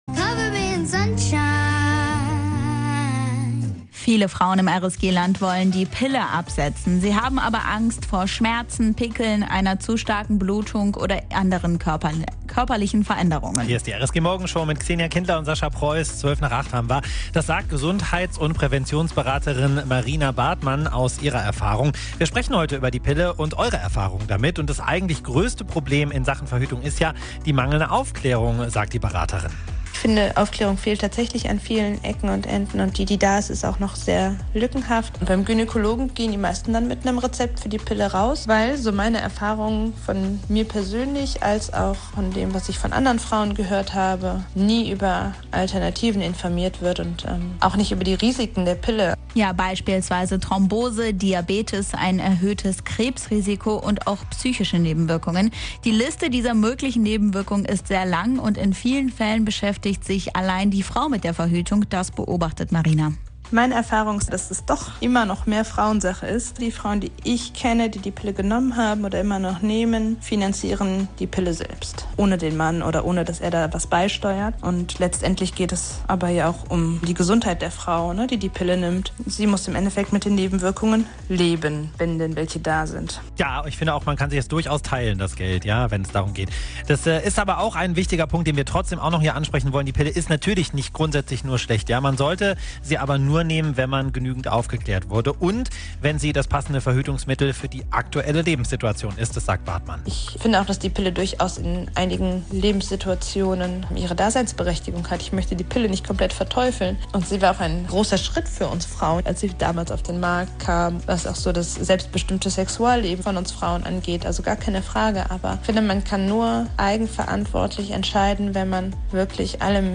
Wir haben mit Frauen aus dem RSG-Land über die Pille und alternative Verhütungsmethoden gesprochen.